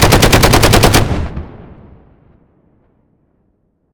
machineout.ogg